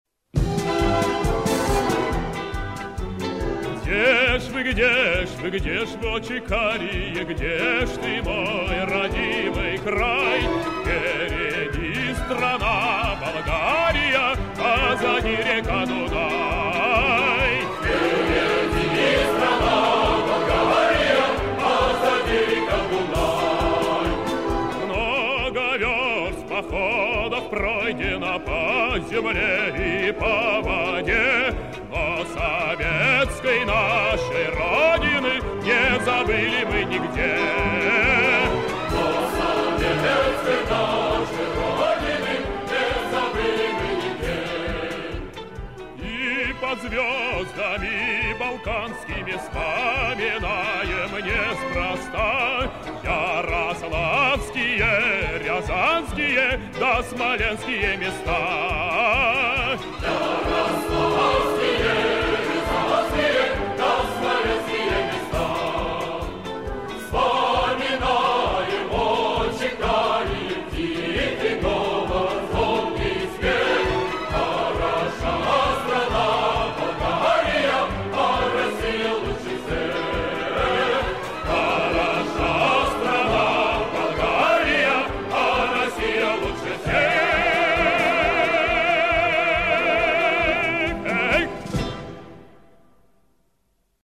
Певцы